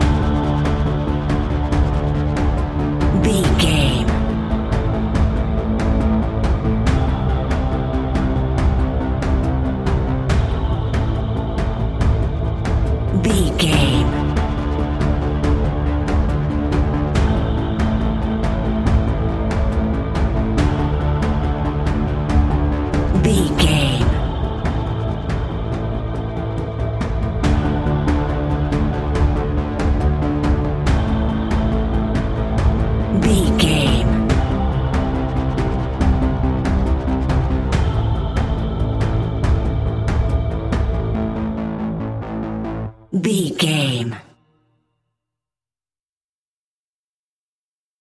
In-crescendo
Thriller
Aeolian/Minor
ominous
suspense
haunting
eerie
instrumentals
horror music
Horror Pads
horror piano
Horror Synths